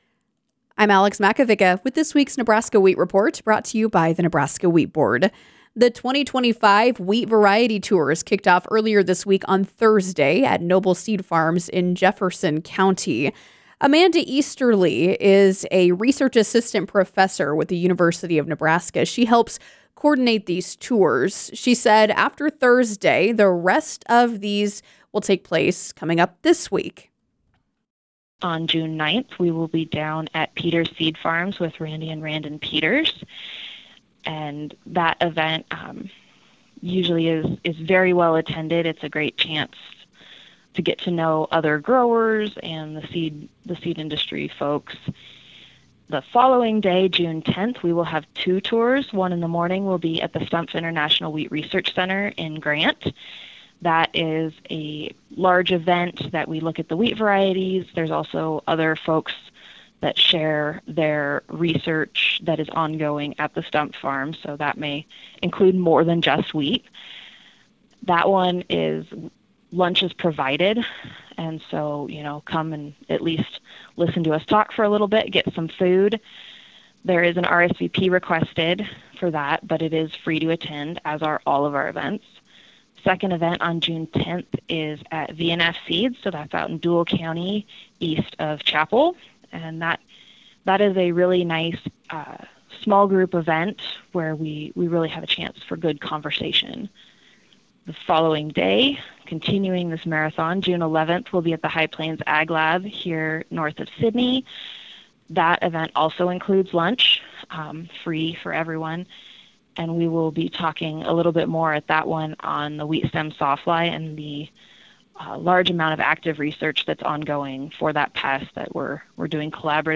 The Wheat Report are interviews conducted with farmers and wheat industry representatives regarding current events and issues pertaining to the Nebraska Wheat Board.